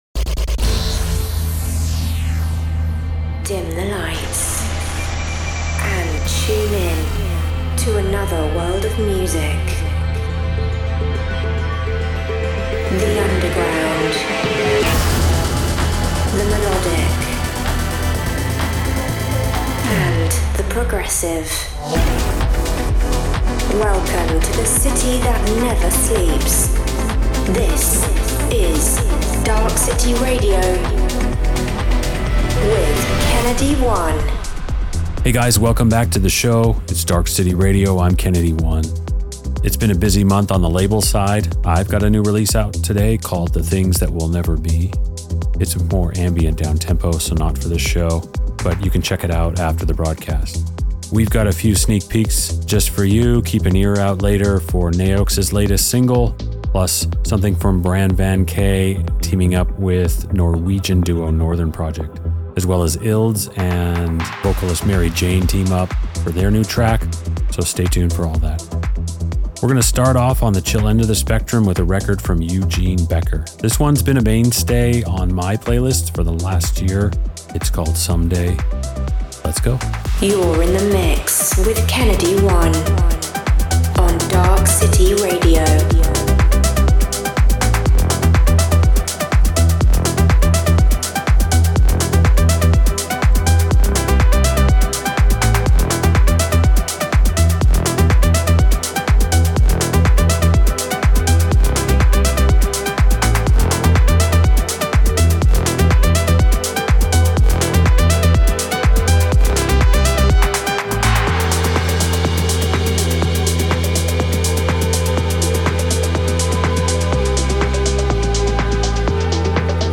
underground melodic techno